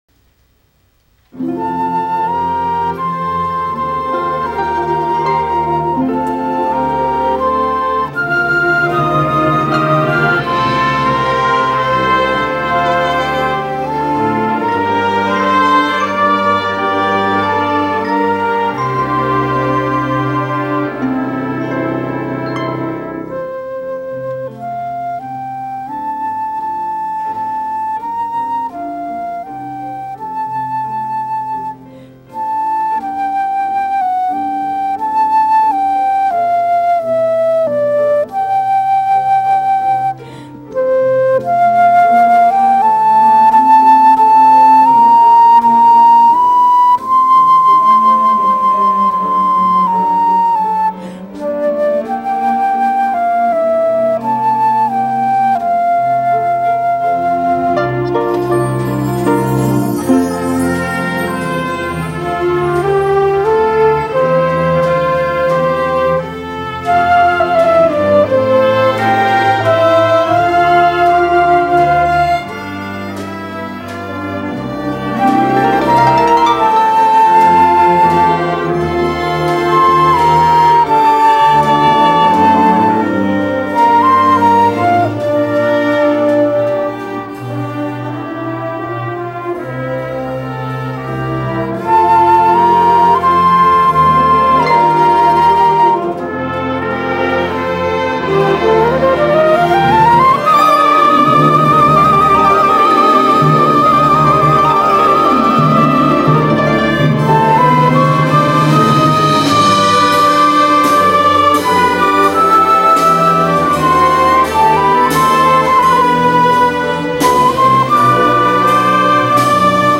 “Moment By Moment” ~ Faith Baptist Orchestra